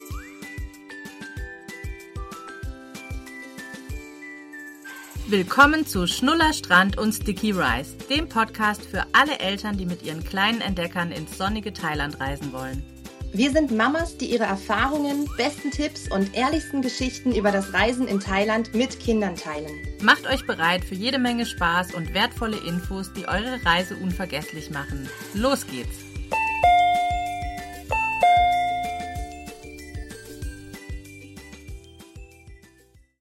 Musik: “The Cooking – Happy Food Music” von SunnyVibesAudio
- Convenience Store Door Chime (32bit, 48kHz, Stereo) by